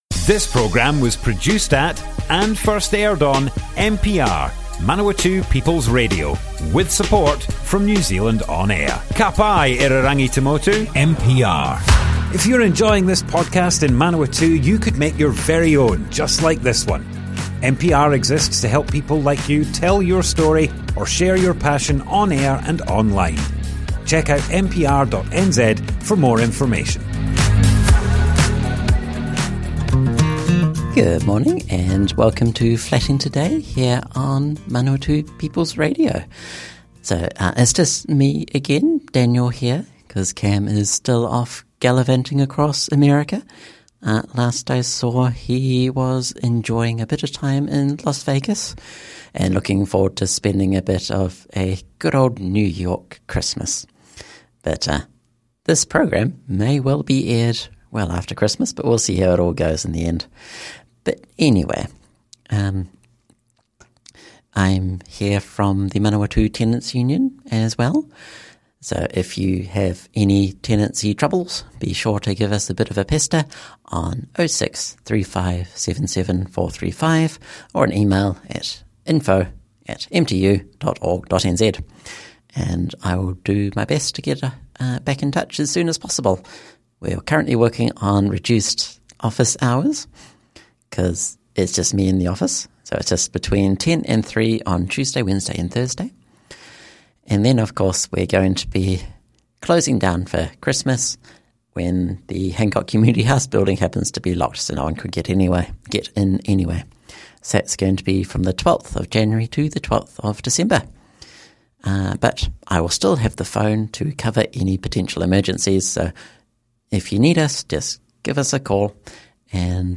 Featuring talks with renters, landlords, property managers, and representatives from other organisations that work in housing.